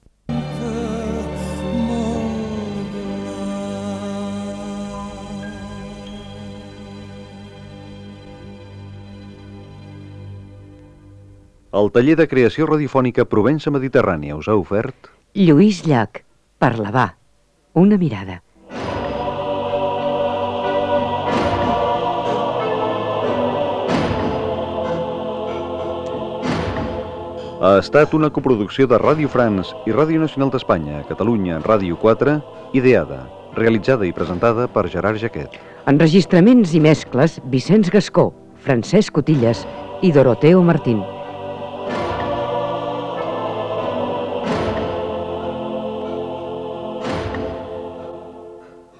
Careta de sortida amb els noms de l'equip
Sèrie composta de vint capítols de dotze minuts cada un, enregistrada a Parlavà el juny de 1991 i coproduïda en col·laboració amb Ràdio França-Rosselló